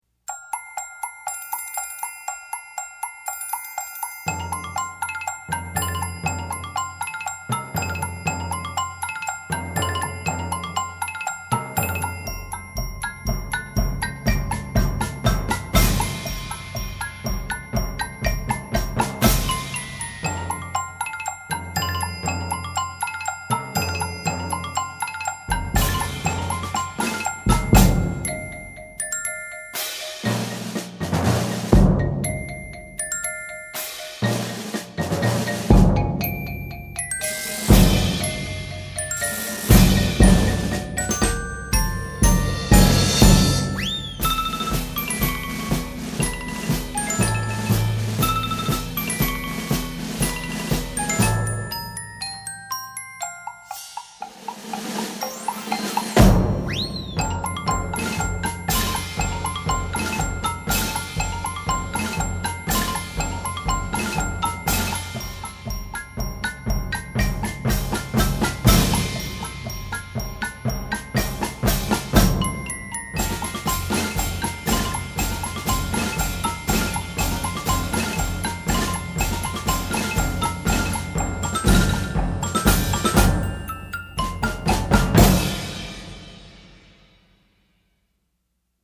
Voicing: 7-8 Percussion